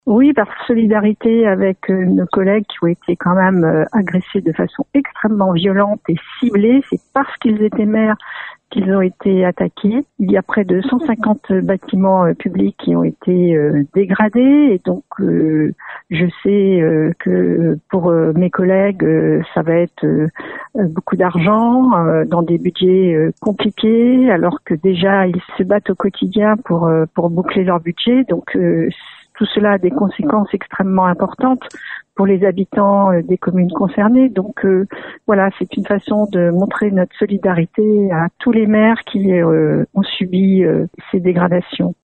Saint-Jean-d’Angély où une minute de silence a été observée par la population et les élus au premier rang desquels le maire Françoise Mesnard a tenu à apporter son soutien aux élus victimes d’agressions :